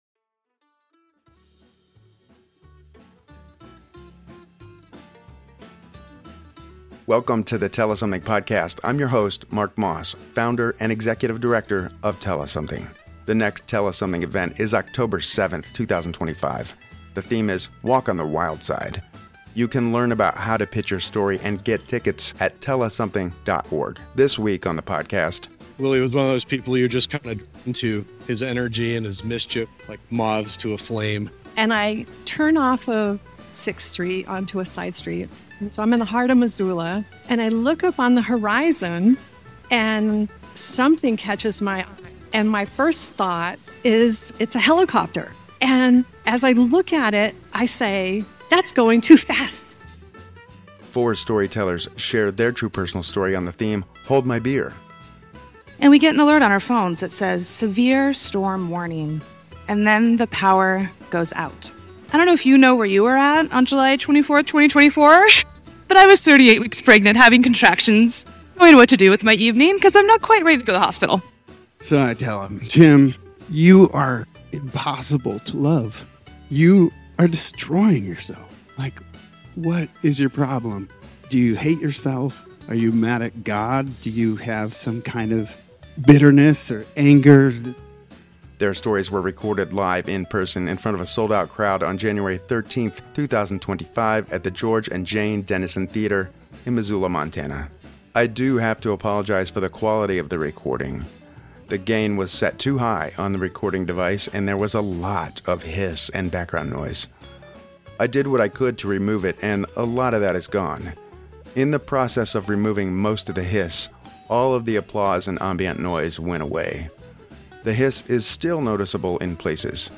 From navigating a life-changing diagnosis in London to a perilous encounter with a glacier in Alaska. Four storytellers share their true personal story on the theme “Hold my Beer”. Their stories were recorded live in-person in front of a sold out crowd on January 13, 2025, at The George and Jane Dennison Theatre in Missoula, MT.